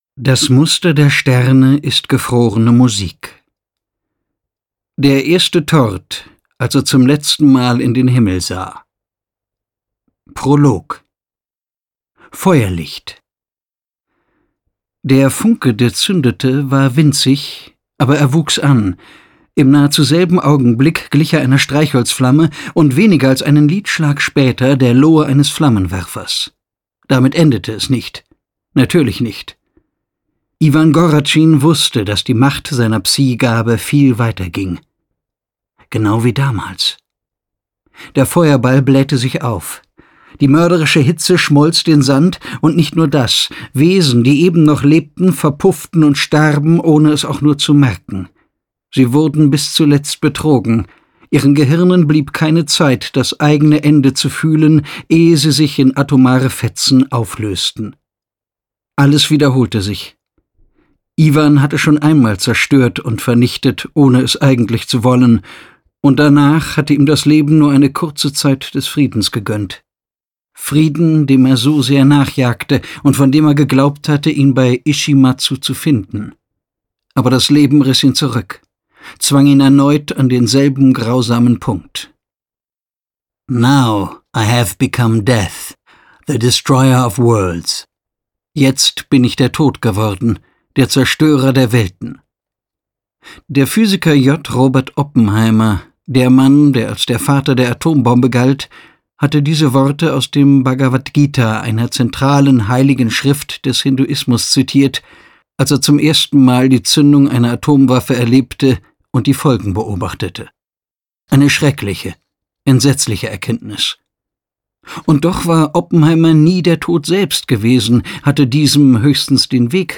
Zusätzliche Formate: E-Book, Hörbuch, enthalten in Platin-Edition 4